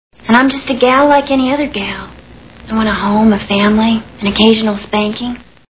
Loaded Weapon 1 Movie Sound Bites